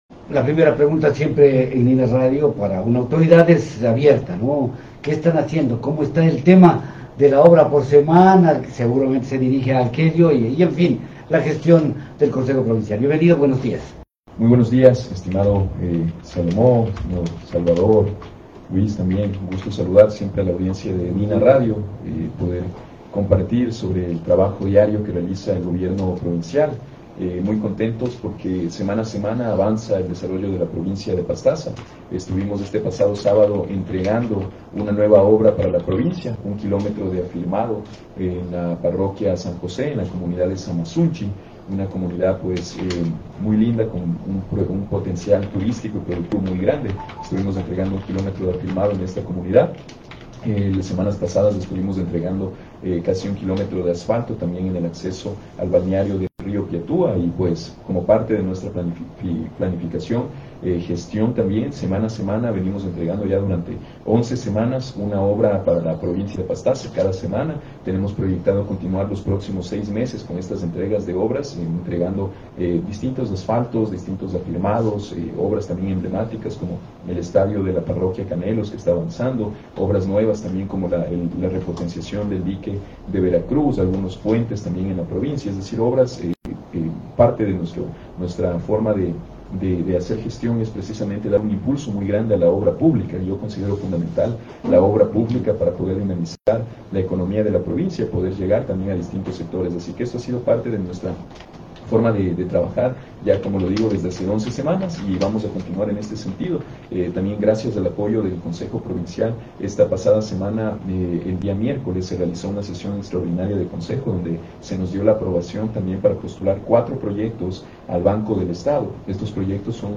En una entrevista concedida por el prefecto André Granda, a Nina Radio el 24 de febrero de 2025, resaltó todo lo ejecutado hasta el momento por la prefectura, principalmente trabajos de lastrado y asfalto en vías hacia las comunidades.